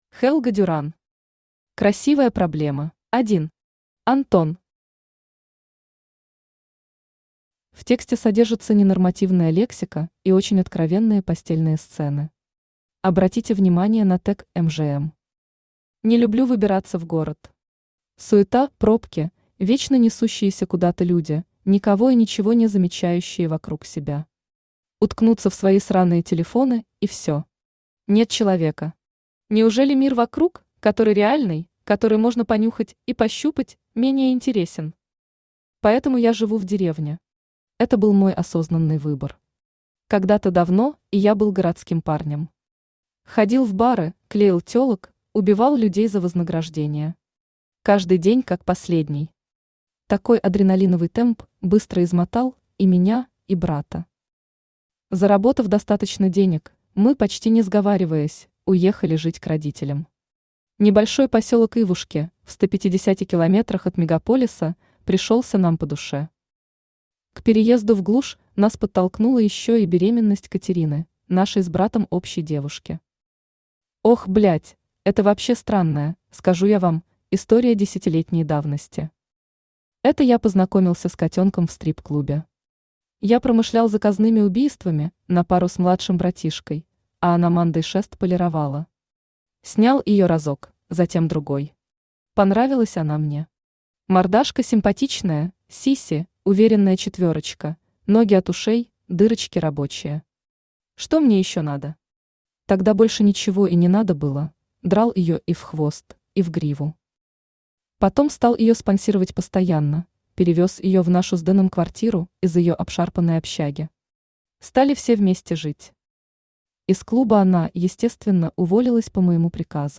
Aудиокнига Красивая проблема Автор Helga Duran Читает аудиокнигу Авточтец ЛитРес.